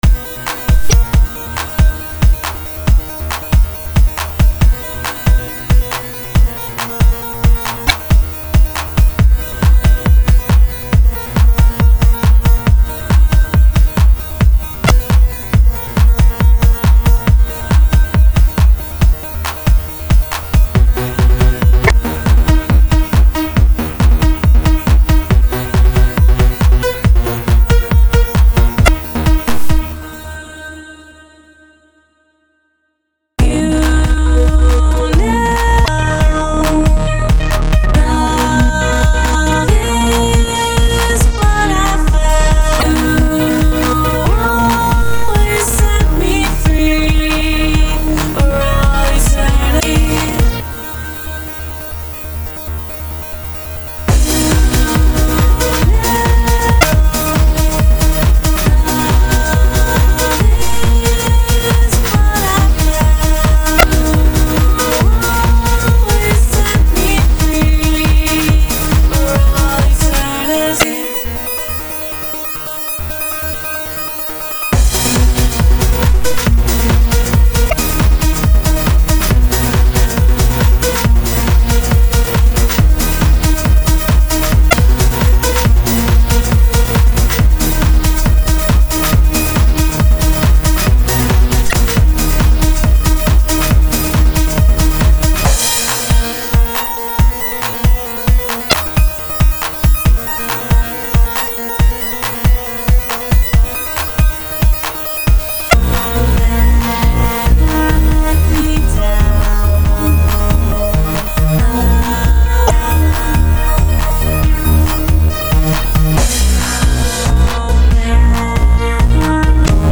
Genre...........: Trance